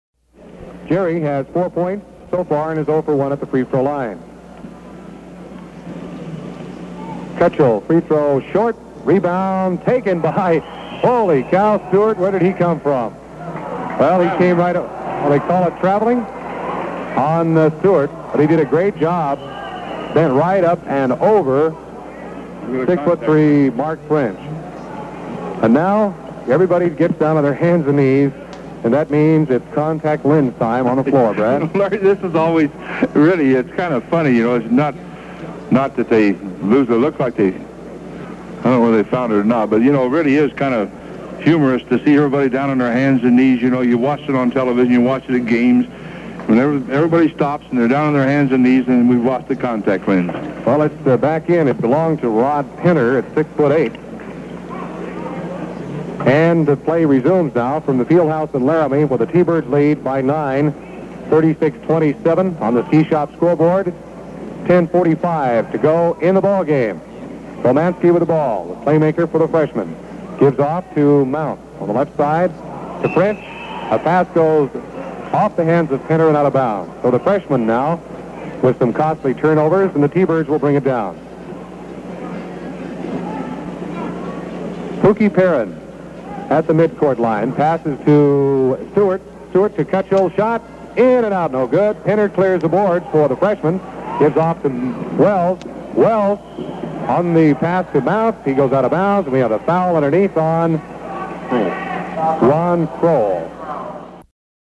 by T-Bird Basketball from U/W Fieldhouse in Laramie | KATI